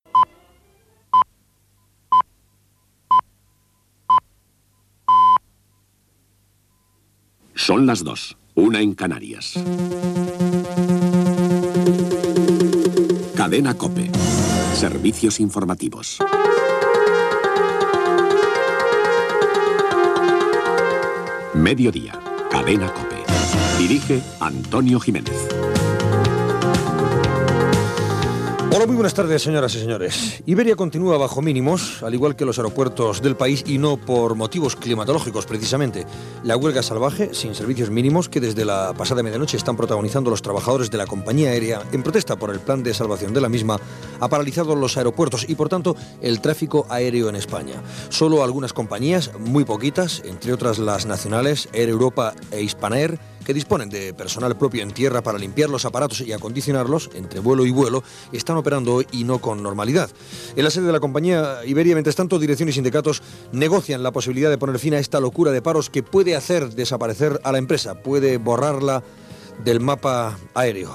Hora, careta del programa, la vaga dels pilots d'Iberia.
Informatiu